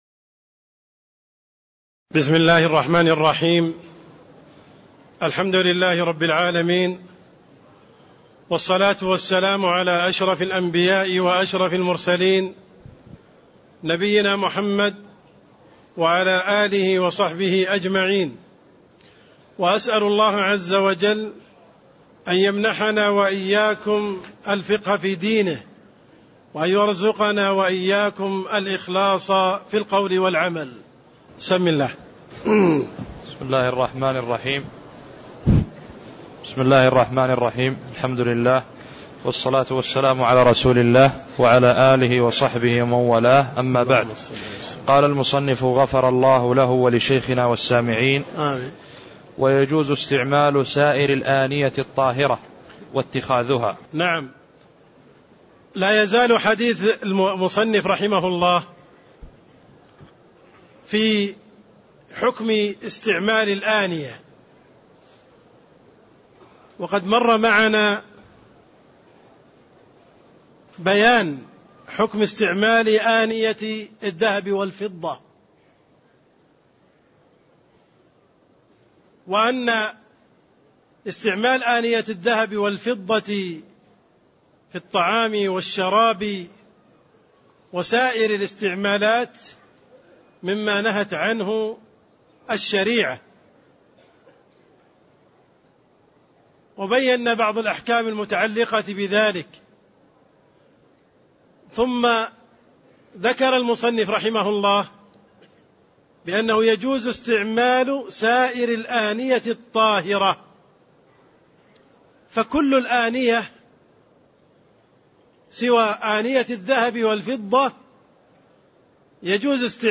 تاريخ النشر ١٠ جمادى الأولى ١٤٣٥ هـ المكان: المسجد النبوي الشيخ